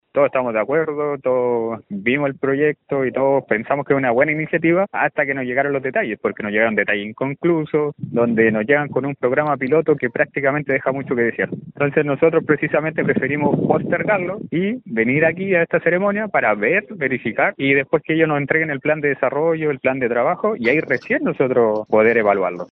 Isaac Núñez es uno de los cinco concejales que se opuso a aprobar el presupuesto. Este señaló que aunque están de acuerdo con los fines de la iniciativa, deben tener el resguardo de que se ocupe bien el dinero municipal.